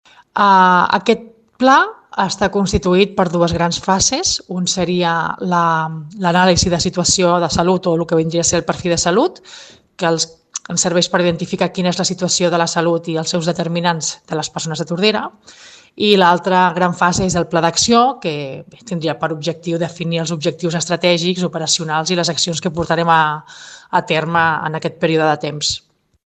El procés d’elaboració del Pla Local de Salut té dues grans fases: la fase d’anàlisi de situació de salut o perfil de salut i l’elaboració del Pla d’acció. Ho explica la regidora de Sanitat, Nàdia Cantero.